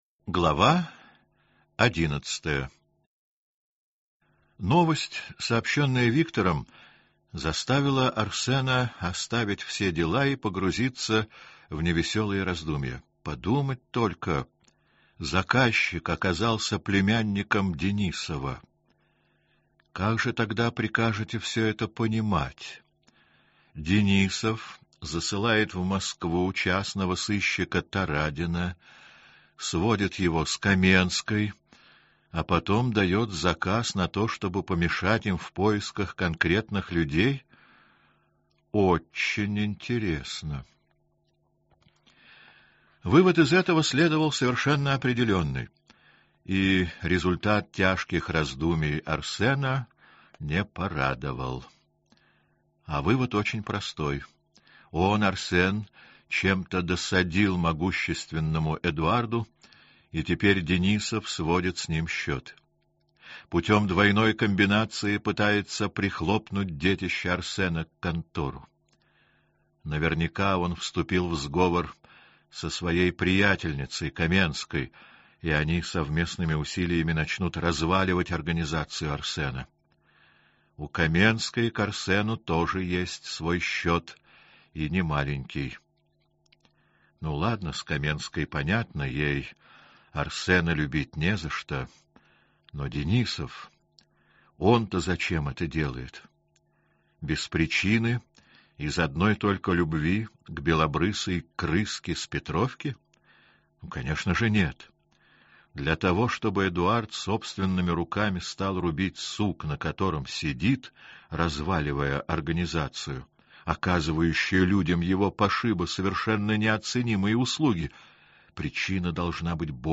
Аудиокнига За все надо платить. Часть 2 | Библиотека аудиокниг